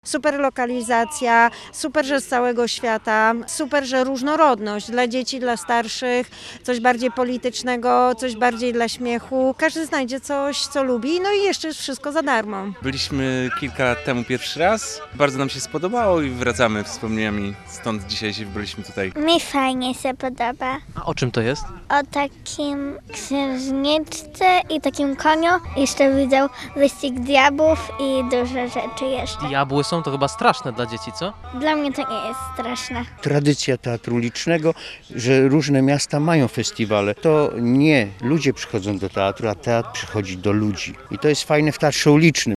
Klaunada, cyrkowe akrobacje, emocjonalne przedstawienia, tańce oraz spektakle lalkowe – w Gdańsku trwa drugi dzień festiwalu FETA. To jedno z najważniejszych wydarzeń w Polsce poświęconych teatrom plenerowym i ulicznym.